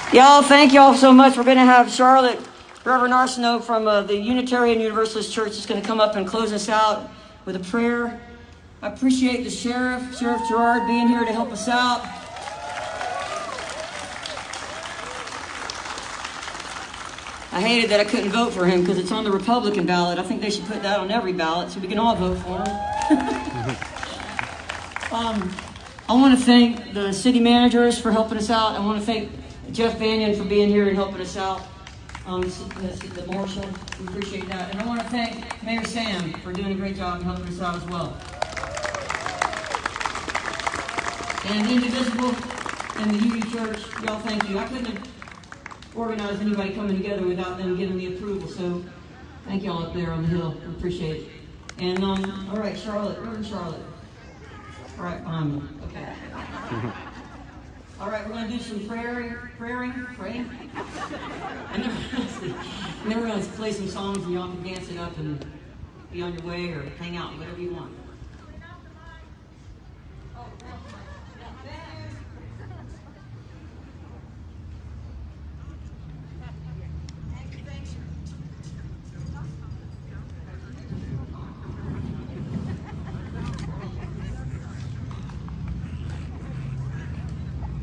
lifeblood: bootlegs: 2020-06-14: peace and unity rally at hancock park - dahlonega, georgia (amy ray)
(captured from a facebook livestream)